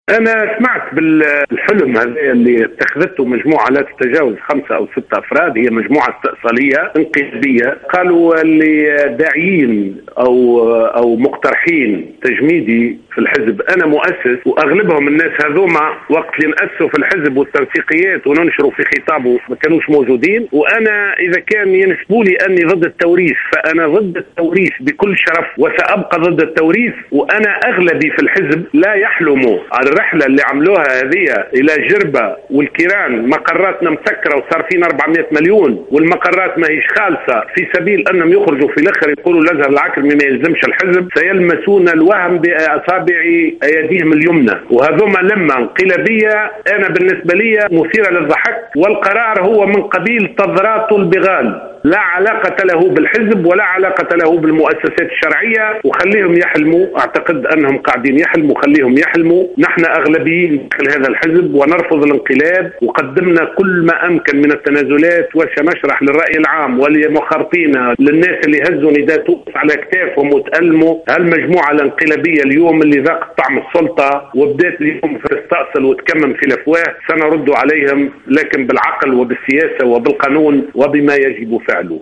وقال العكرمي في تصريح للجوهرة أف أم اليوم الأحد، أن المجموعة المجتمعة في جربة والتي أنفقت نحو 400 ألف دينار على هذا الاجتماع ستفشل في مسعاها لإقصائه من الحزب، وأن الرد عليهم سيأتي بـ"السياسة والعقل والقانون" وفق تعبيره.